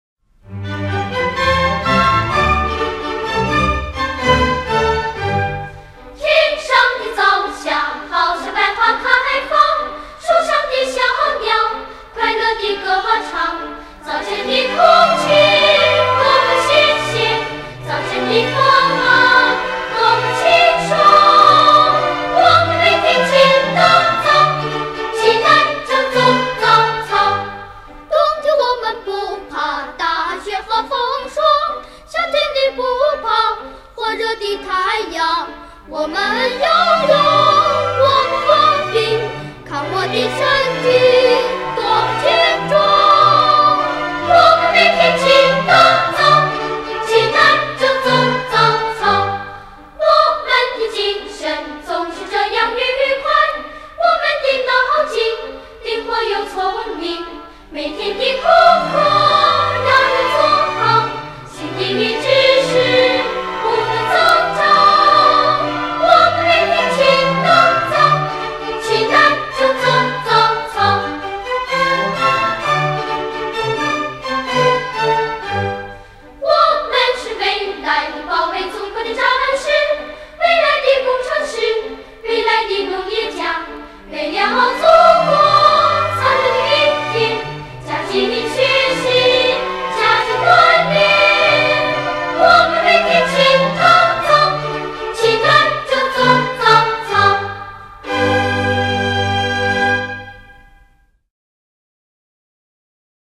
低音质试听： (WMV/128K)